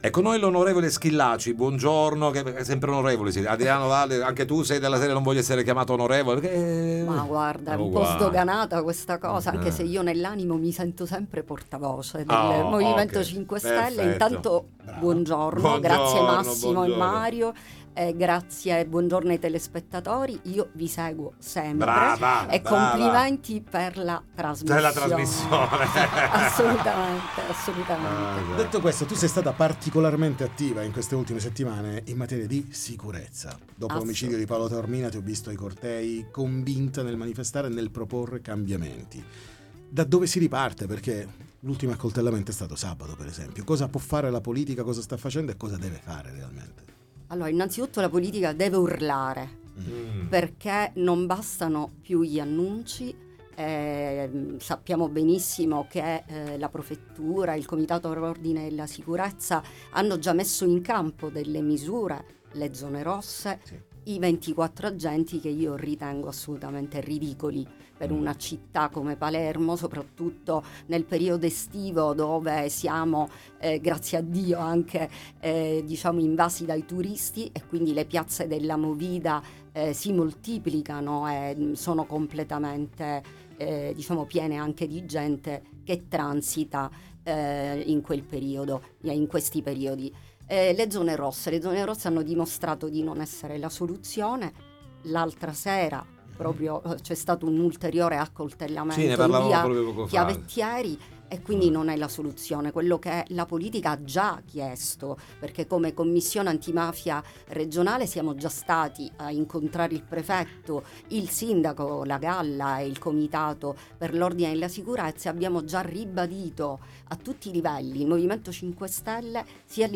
Roberta Schillaci del M5S Interviste Time Magazine 27/10/2025 12:00:00 AM / Time Magazine Condividi: Sicurezza a Palermo con l’On.